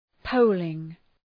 Προφορά
{‘pəʋlıŋ}